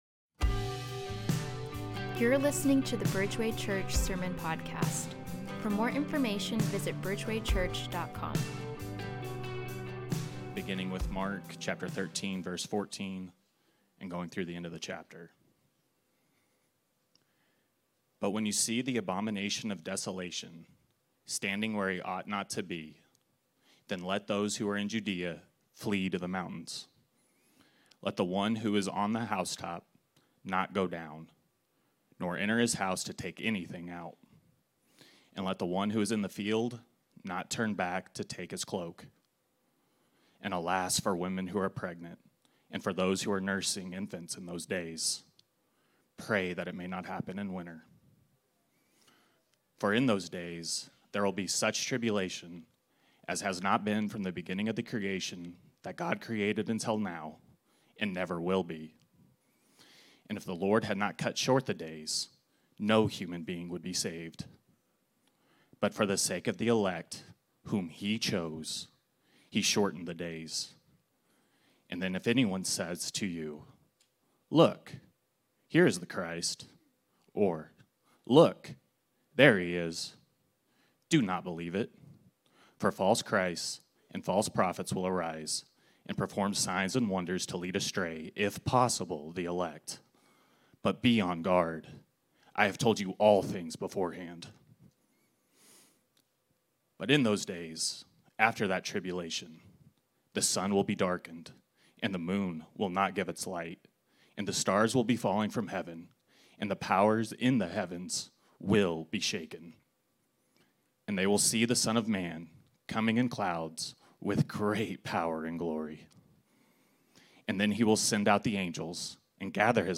march-9-2025-sermon-audio.m4a